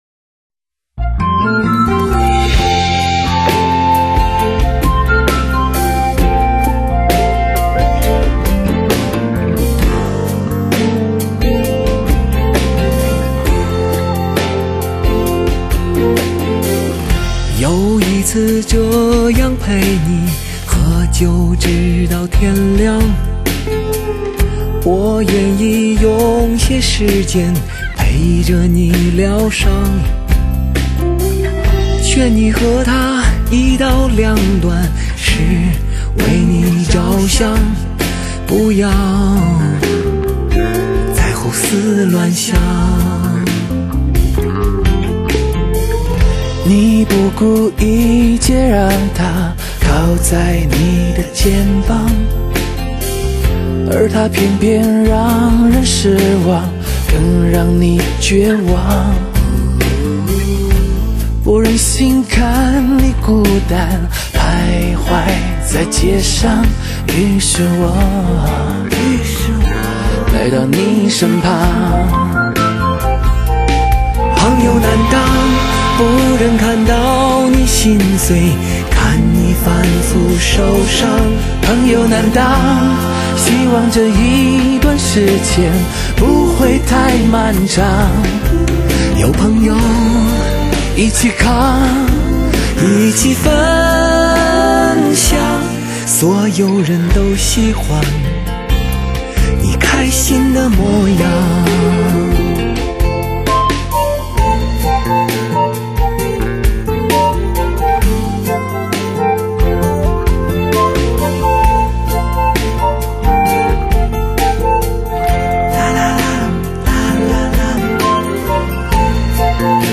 惯有的城市民谣的姿态
温馨、唯美的旋律、极度生活化的歌词，低吟轻唱之间喷涌出都市人群的某一个侧面。